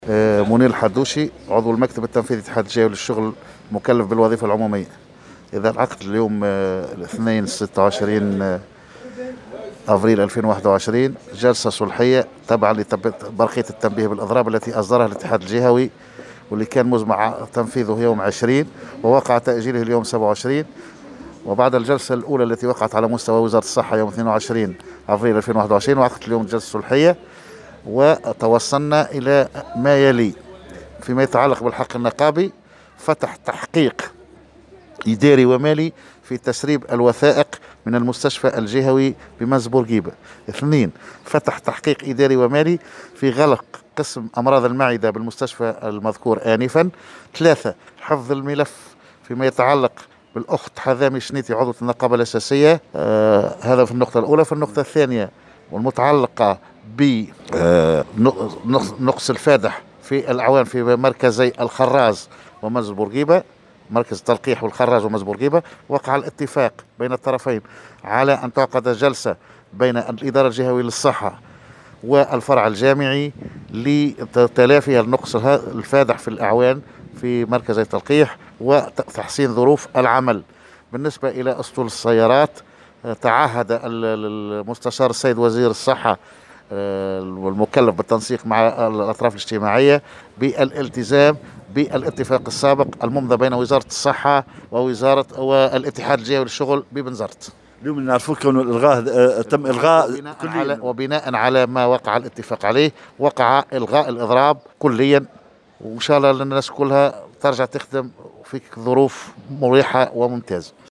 أكثر تفاصيل في تصريح